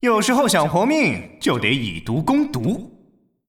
移动语音